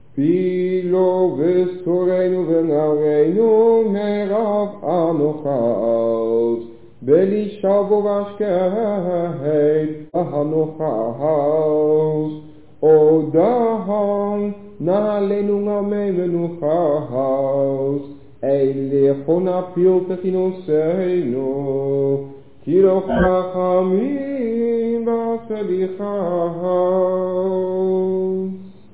op cassettebandjes.